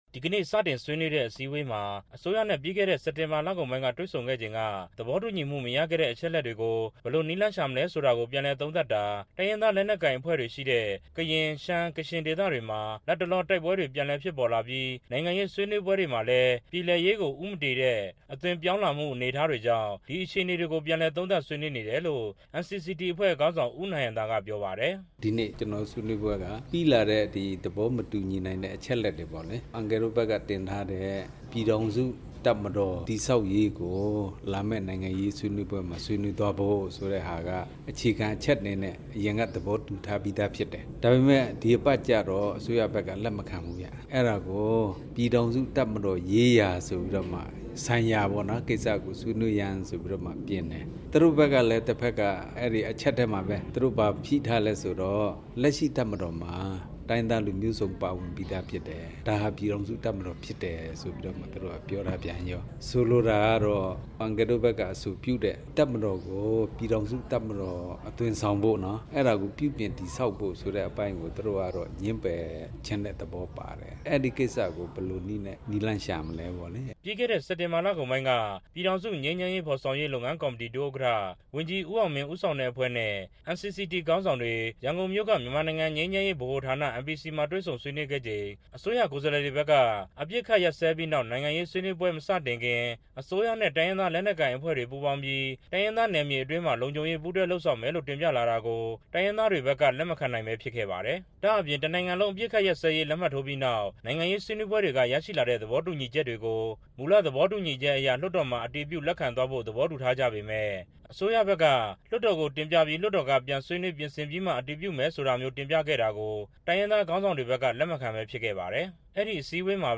သတင်းပေးပို့ချက်